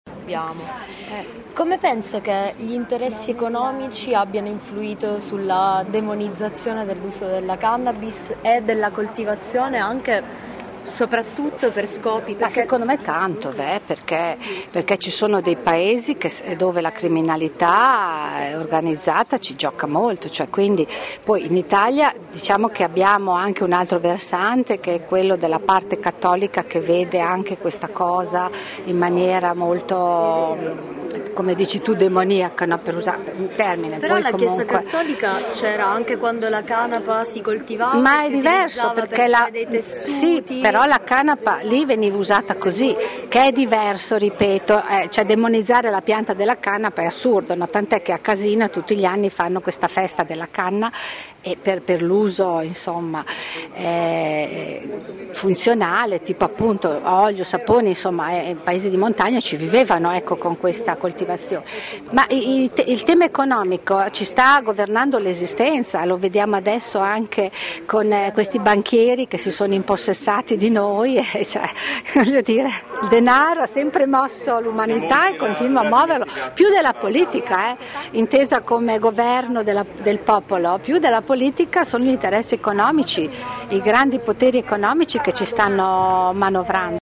9 lug. – Cartelli e bandiere per la legalizzazione della marijuana, anche se solo a scopo terapeutico, hanno campeggiato in viale Aldo Moro, al presidio organizzato dai Radicali.
Dietro alla demonizzazione della cannabis ci sono ragioni economiche molto forti, ha detto il capogruppo dell’Idv Liana Barbati.